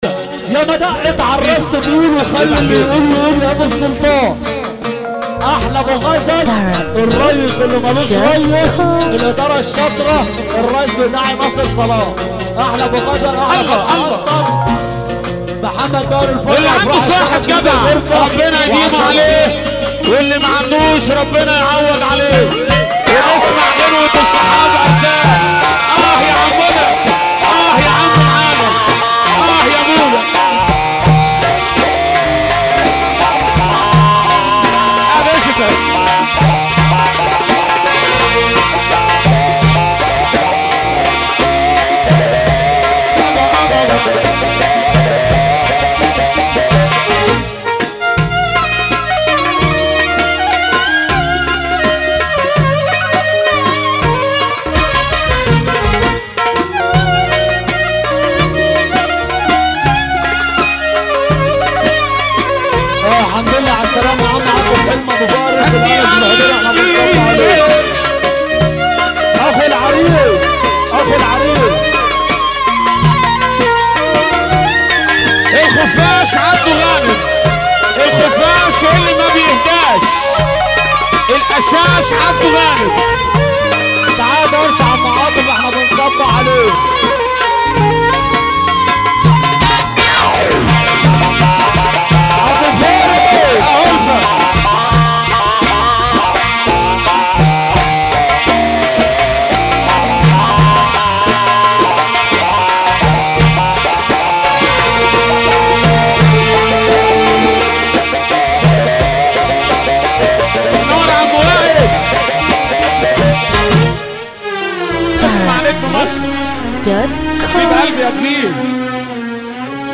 موال
بشكل حزين جدا